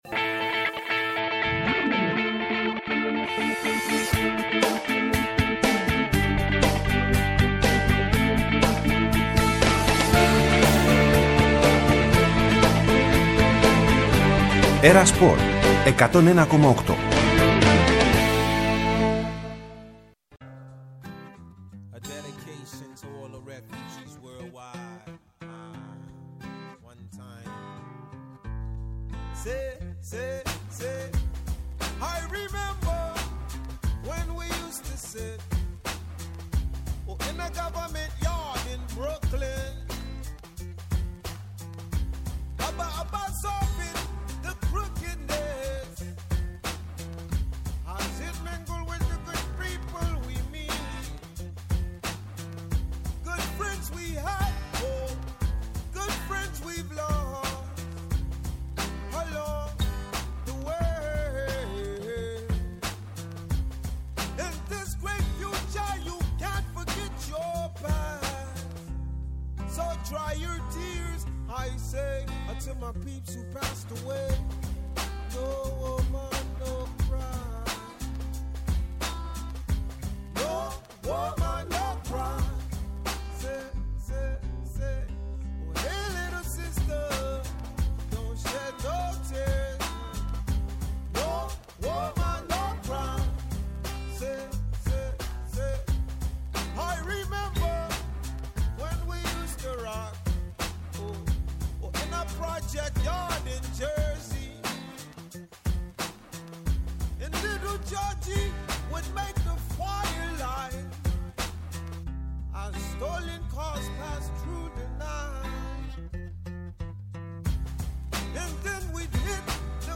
Έκτακτη εκπομπή, από το περίπτερο της ΕΡΑ Σπορ στην έκθεση “Marathon Expo” του Π. Φαλήρου